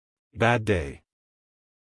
a-bad-day-us-male.mp3